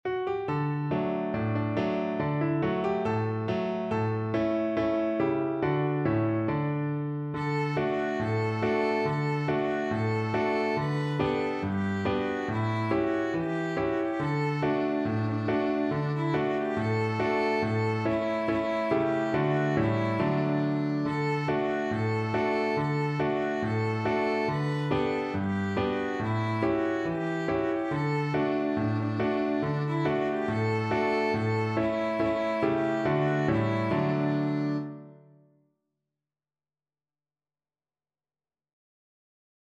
Happily =c.140
4/4 (View more 4/4 Music)
Classical (View more Classical Violin Music)